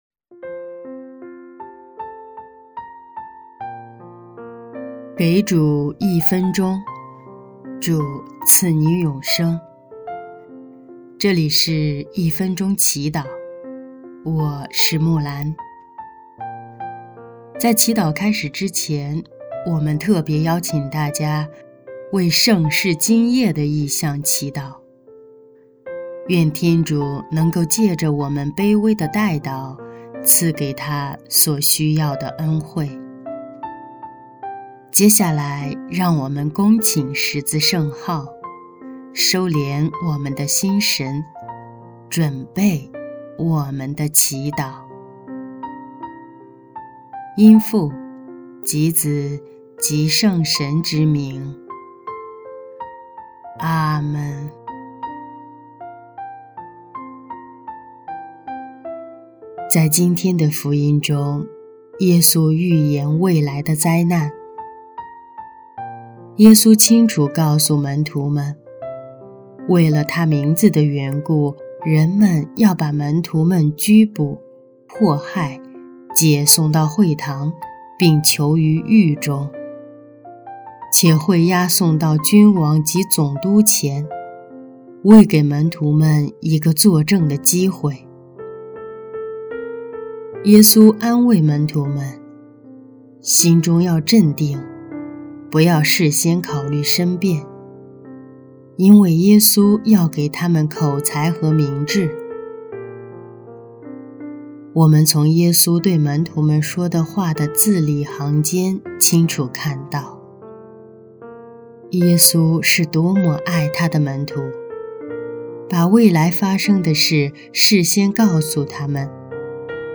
【一分钟祈祷】| 11月23日 主，我渴望在每天的生活中按照祢的旨意去生活
音乐：第三届华语大赛参赛作品《为了祢，我愿意继续爱这个世界》